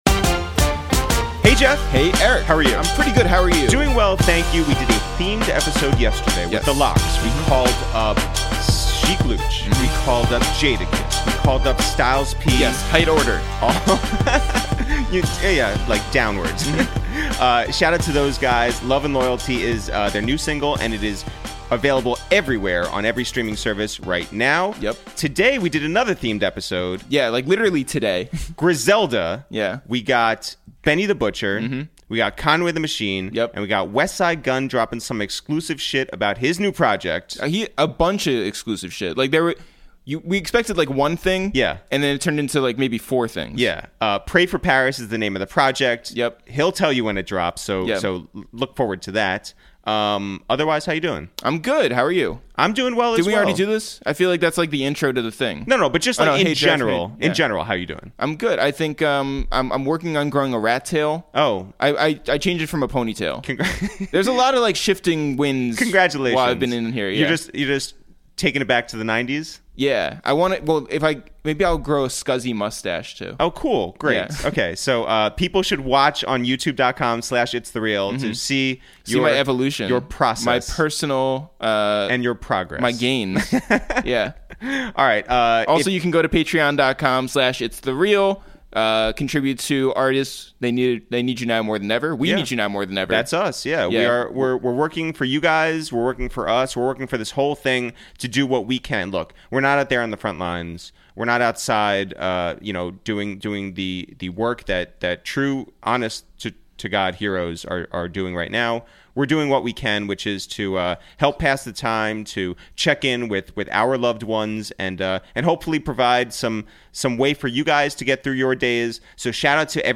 Today on Episode 17 of Quarantine Radio, we make calls from our Upper West Side apartment to check in on Griselda's Westside Gunn, Conway The Machine and Benny The Butcher, who drop exclusives, have some laughs and of course TALK THAT TALK.